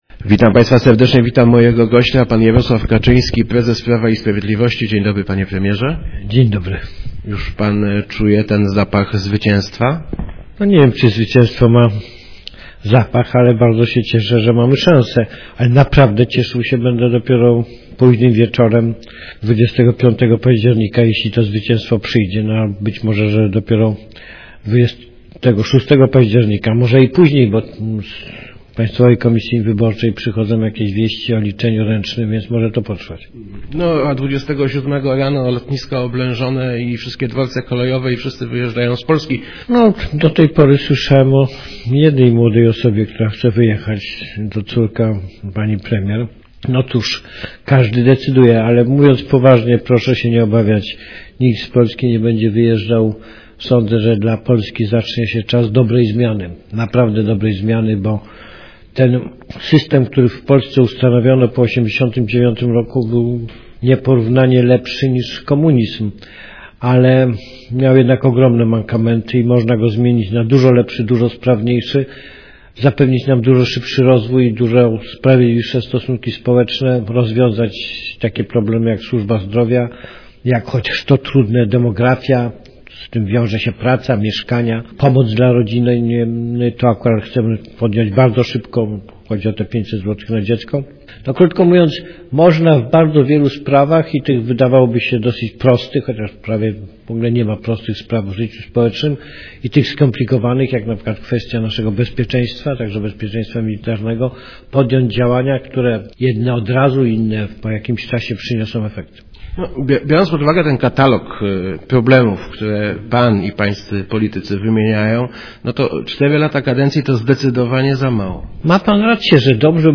Jarosław Kaczyński w Radiu Elka. Albo wszystko, albo nic / Jarosław Kaczyński